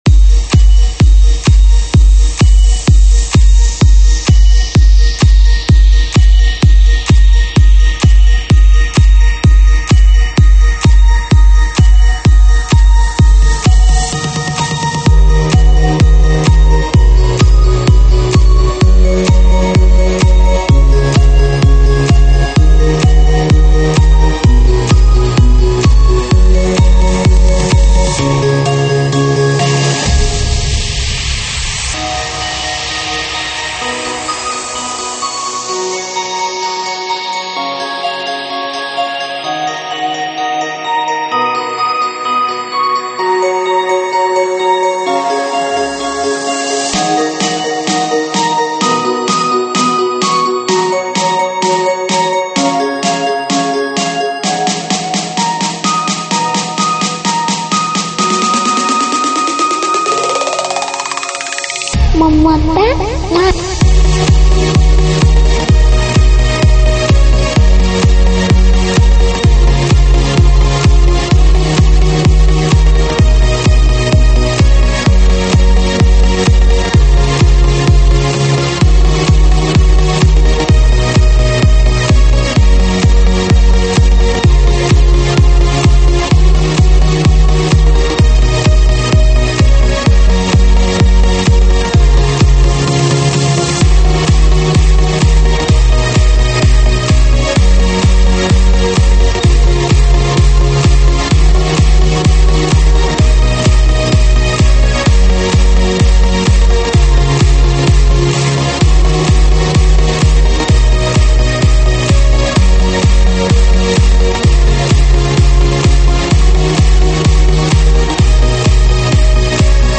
栏目：英文舞曲